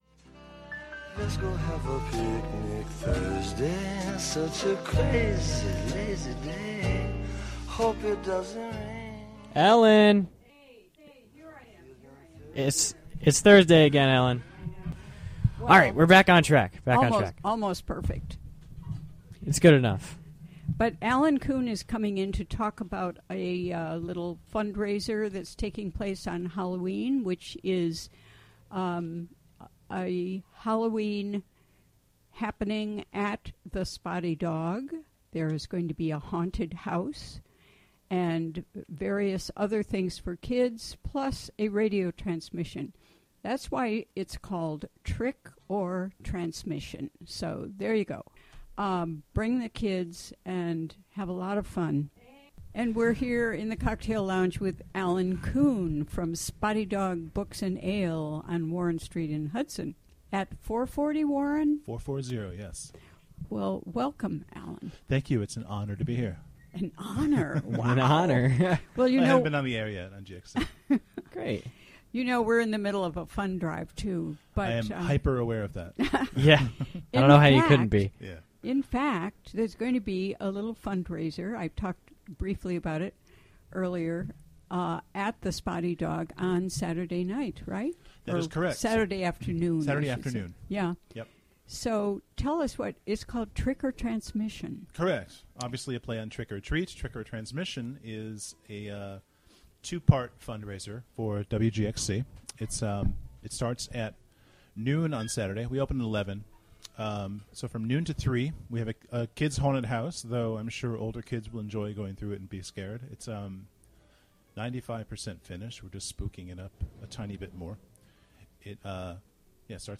In-studio talks